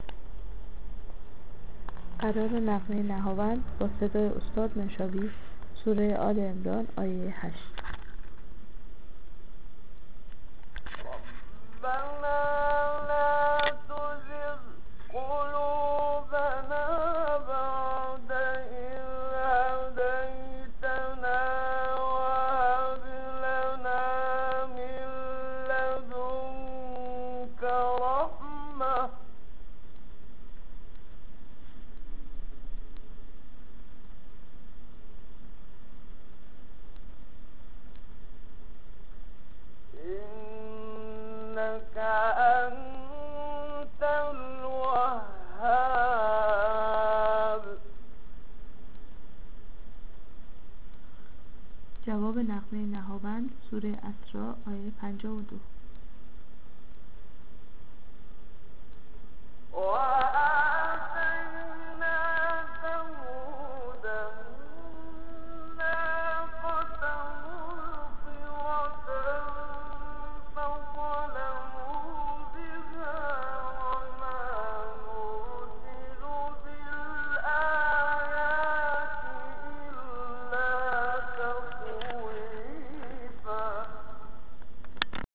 قرار-نهاوند-منشاوی-سوره-آل-عمران-آیه-8.mp3